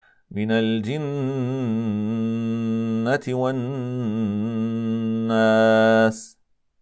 e ـــ Skakar sin ghunnah när den har en shaddah (), vilket sker genom att göra ghunnah vågig och vibrera dess ljud medan den uttalas, som i: